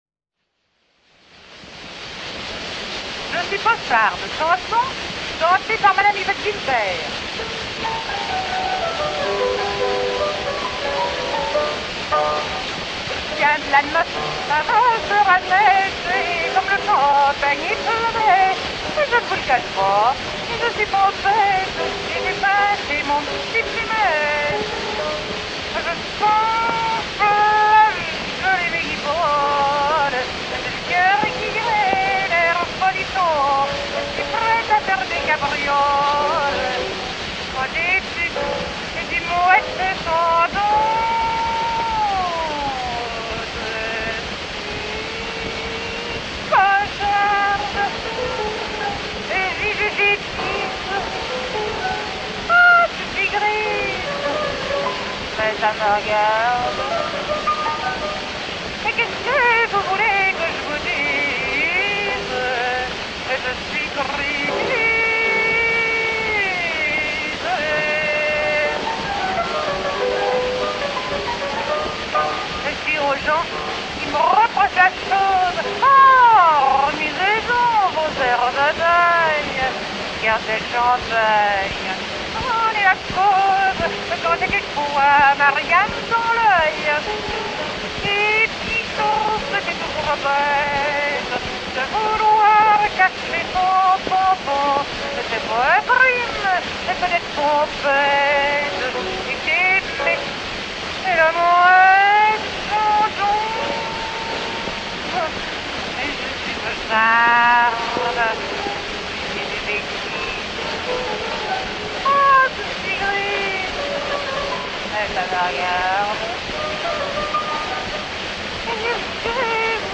une très vieille chose : Yvette Guilbert chante
Yvette Guilbert - Je suis pocharde1897.mp3